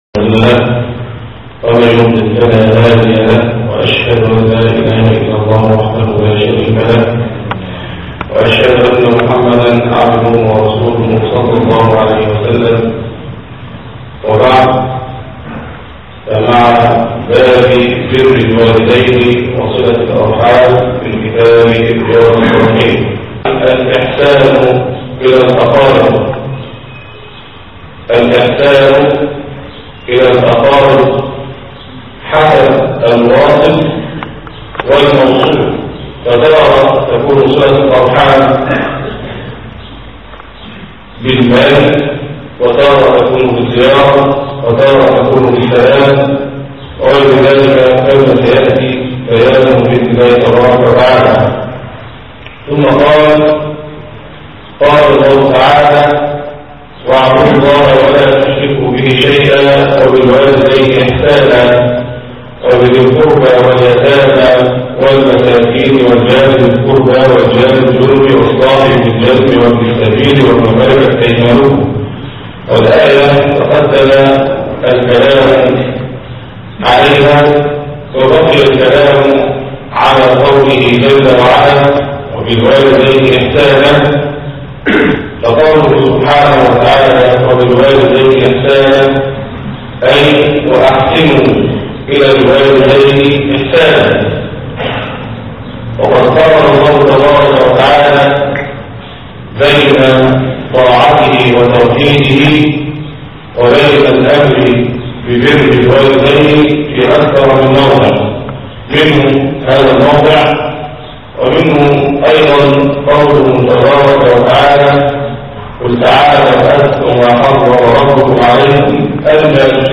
عنوان المادة الدرس الأول "شرح باب بر الوالدين "